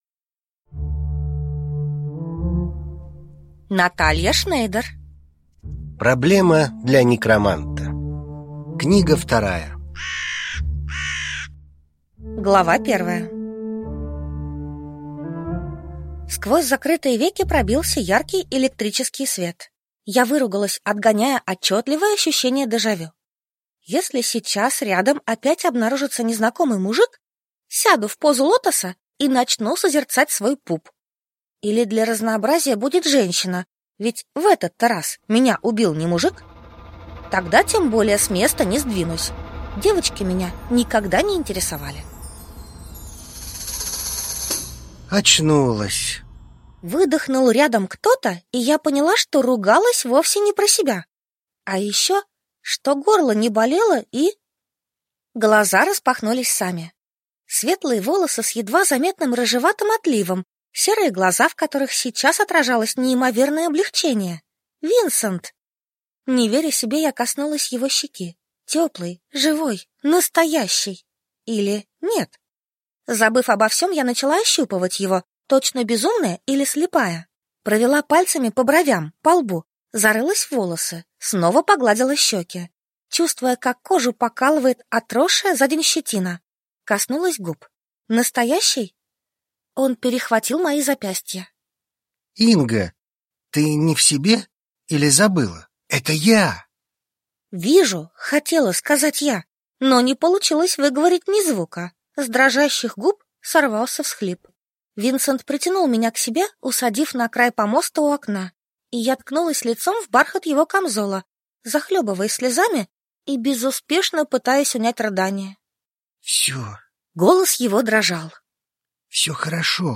Аудиокнига Проблема для некроманта – 2 | Библиотека аудиокниг